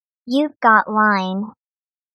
可愛らしい女の子の声が響くLINEの通知音です。